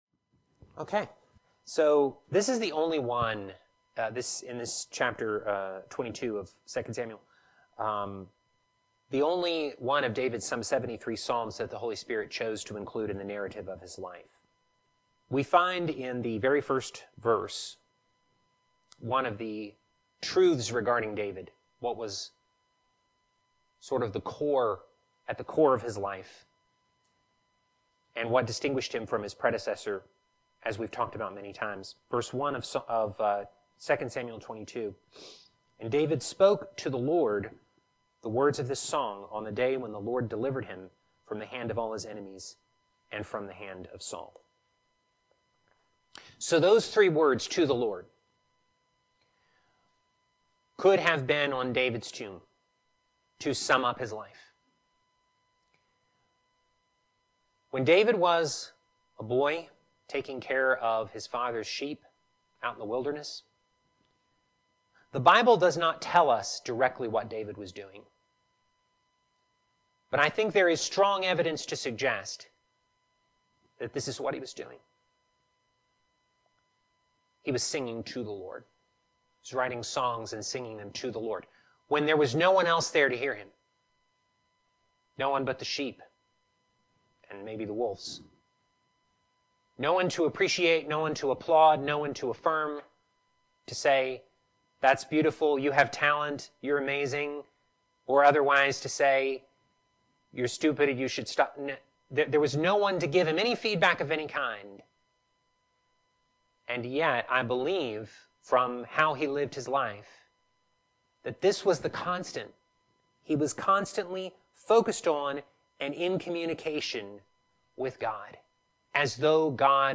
Teaching For February 23, 2025